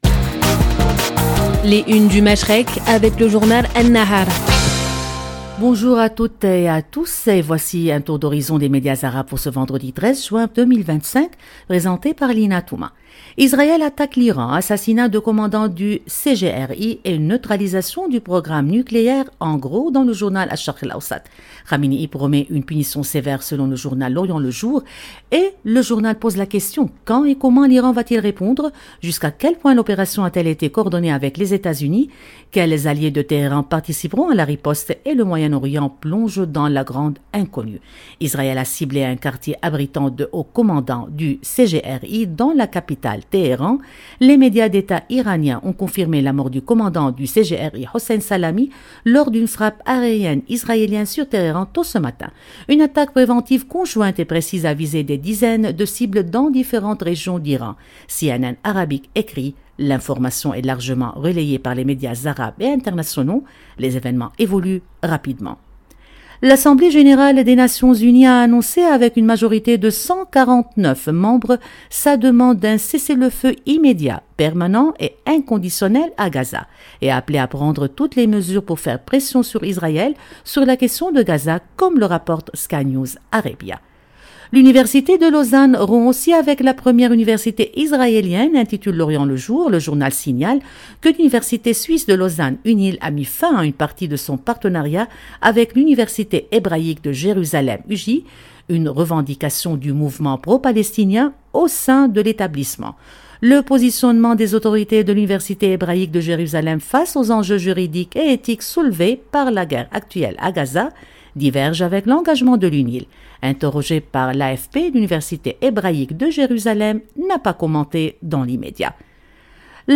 Radio Orient vous présente, en partenariat avec le journal libanais An-Nahar , une revue de presse complète des grands titres du Moyen-Orient et du Golfe. À travers des regards croisés et des analyses approfondies, cette chronique quotidienne offre un décryptage rigoureux de l’actualité politique, sociale et économique de la région, en donnant la parole aux médias arabes pour mieux comprendre les enjeux qui façonnent le Machrek. 0:00 3 min 43 sec